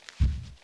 behemoth_walk1.wav